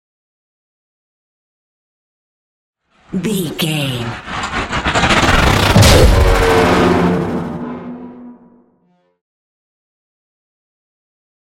Sci fi whoosh to hit big
Sound Effects
dark
futuristic
intense
tension
woosh to hit